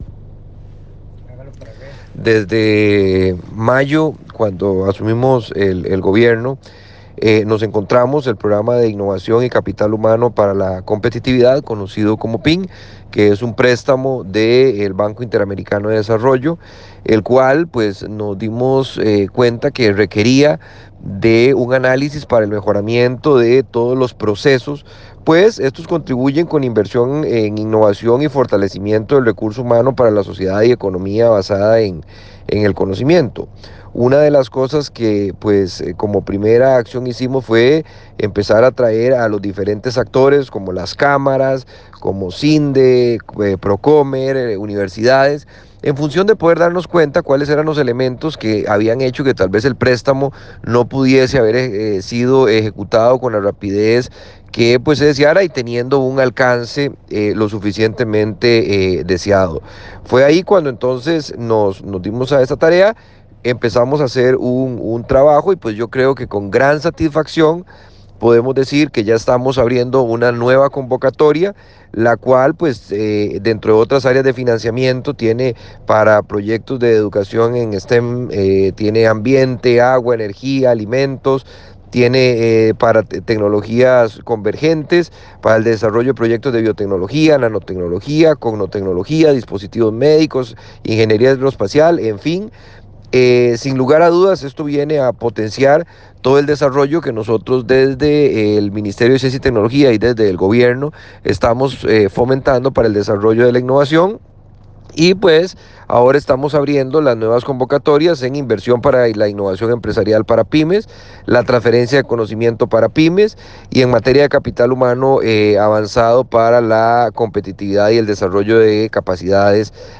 Declaraciones del ministro Luis Adrián Salazar sobre convocatoria para proyectos innovadores con fondos no reembolsables